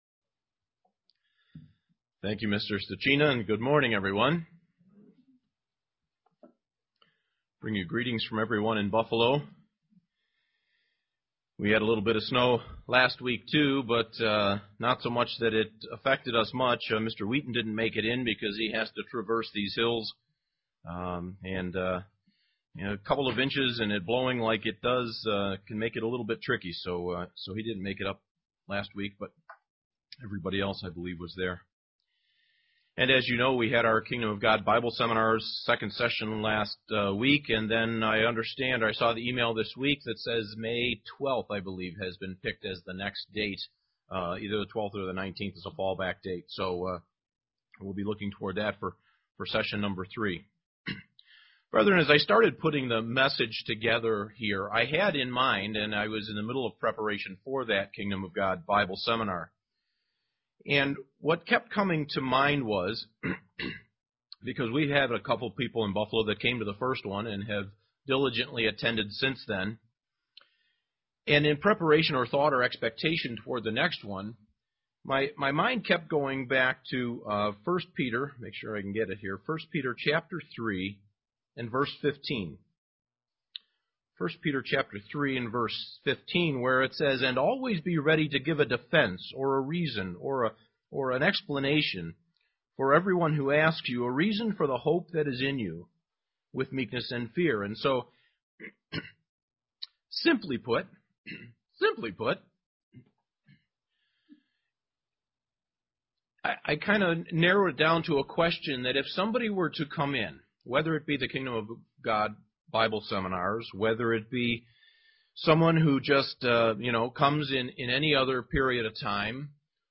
Given in Elmira, NY
Print What is conversion and how it is obtained UCG Sermon Studying the bible?